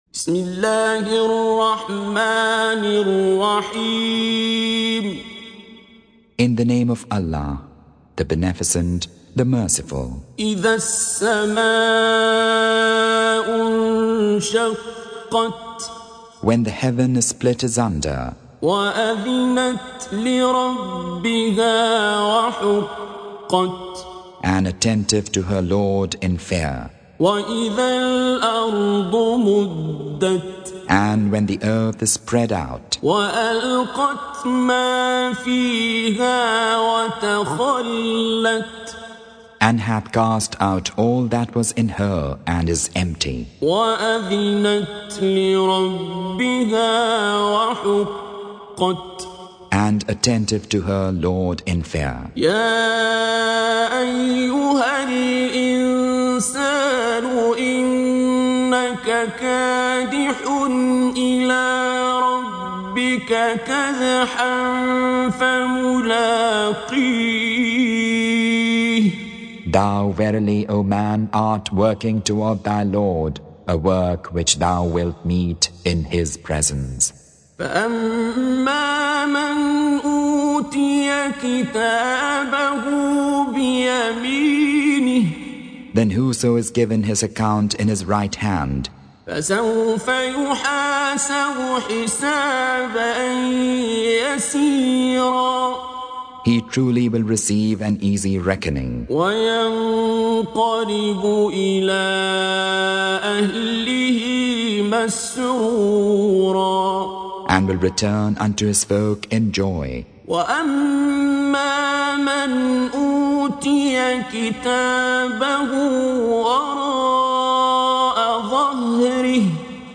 Surah Sequence تتابع السورة Download Surah حمّل السورة Reciting Mutarjamah Translation Audio for 84. Surah Al-Inshiq�q سورة الإنشقاق N.B *Surah Includes Al-Basmalah Reciters Sequents تتابع التلاوات Reciters Repeats تكرار التلاوات